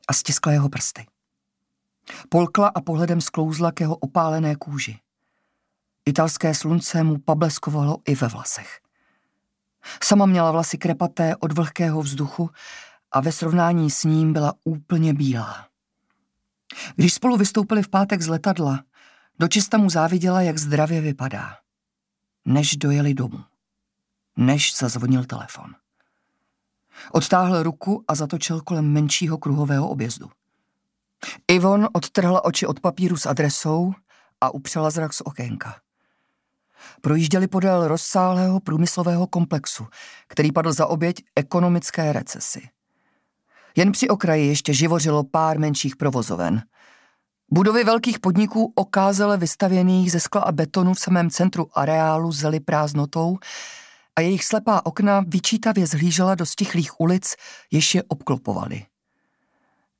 Audiobook
Read: Kristýna Frejová